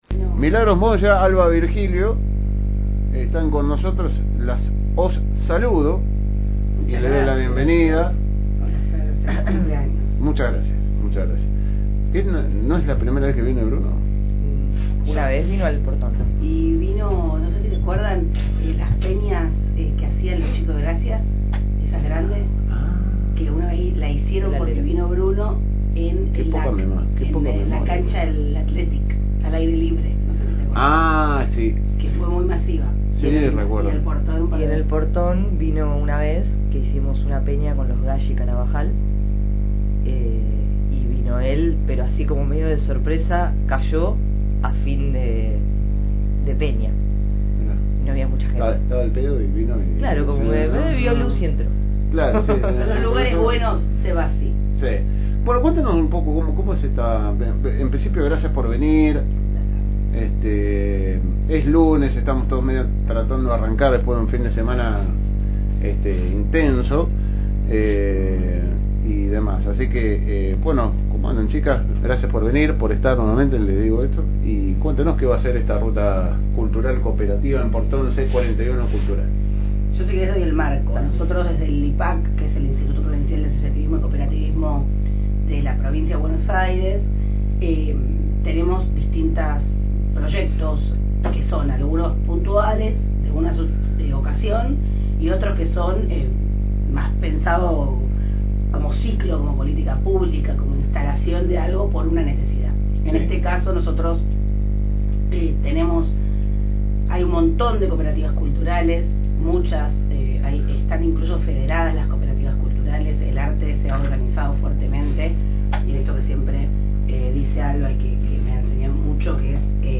estuvieron en la radio FM Reencuentro para comentar y promocionar las actividades que se llevarán a cabo en el Portón y las podes escuchar en el siguiente link.